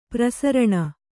♪ prasaraṇa